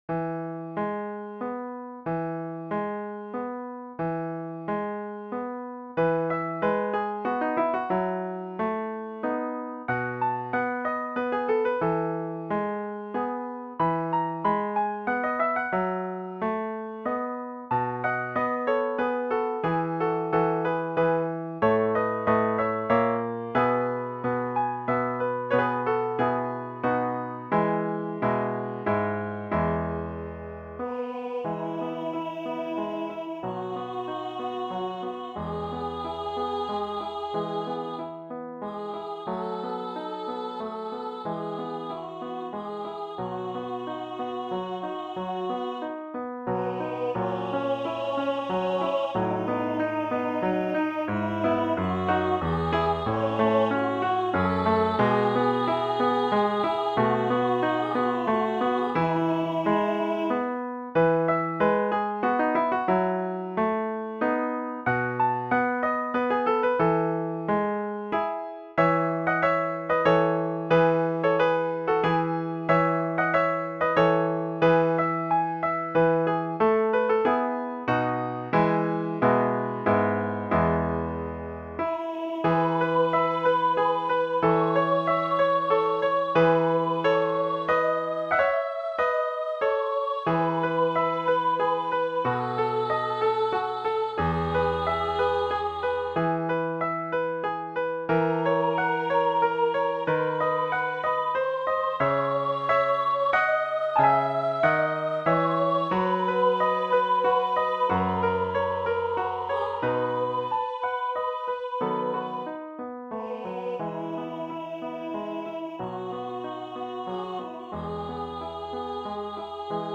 Plaisir d'amour Martini Voice and piano
Classical French song about how love's pleasure lasts but a moment, while love's pain lasts a whole life long.